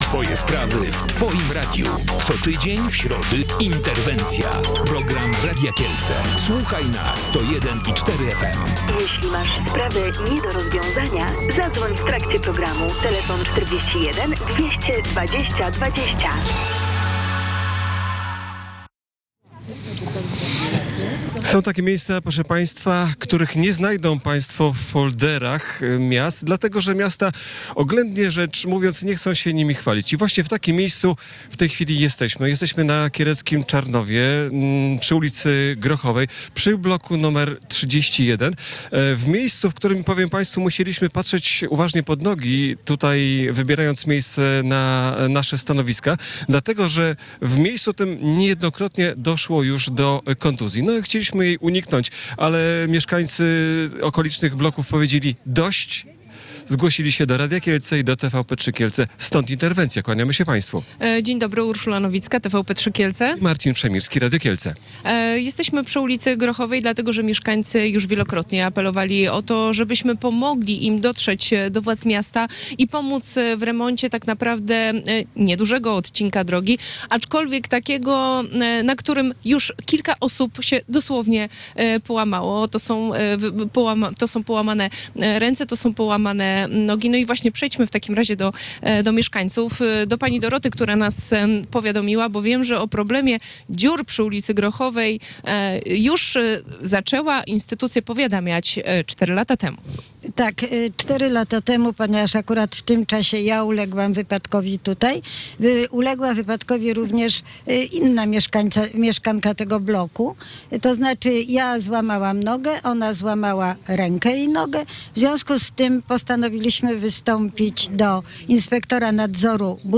Przy bloku nr 31 są dziury w chodniku, wyrwy w jezdni, brak też oświetlenia. W audycji Interwencja pytaliśmy o to, kiedy będzie tu bezpiecznie, a ludzie nie będą łamali sobie kończyn.